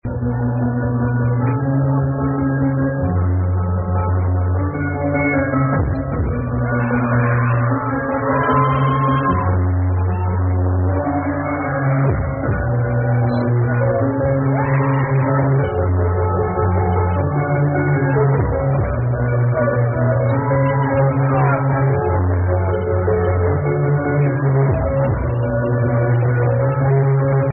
Someone could help me??? the tracks are registered in 1996-2000 live at Cocorico' riccione Italy